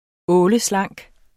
Udtale [ ˈɔːləˈslɑŋˀg ]